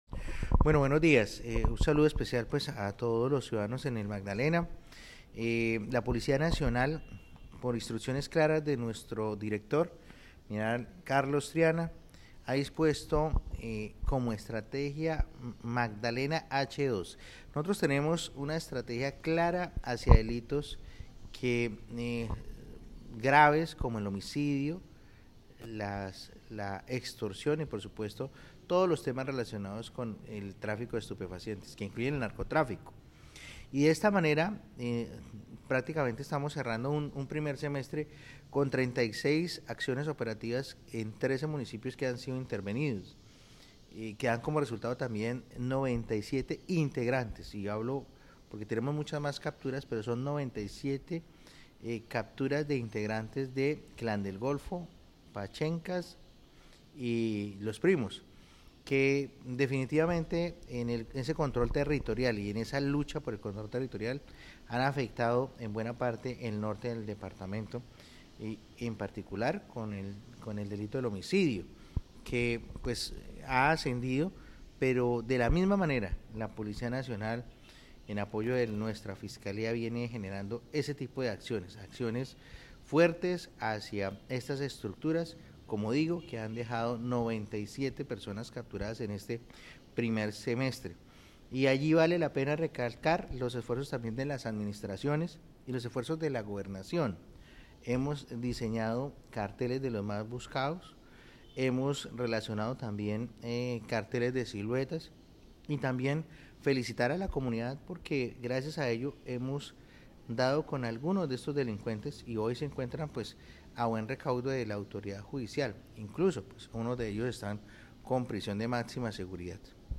CORONEL JAVIER DUARTE, COMANDANTE POLICÍA MAGDALENA